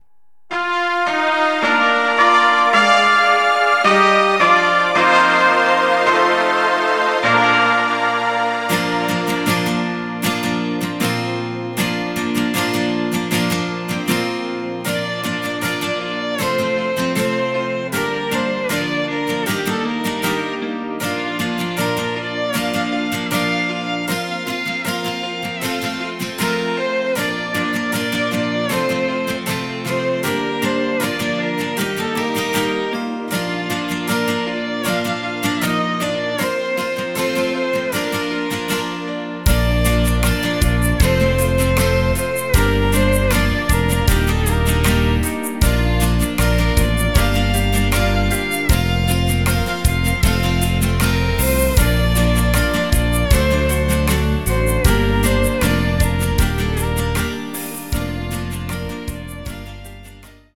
Rhythmus  Slow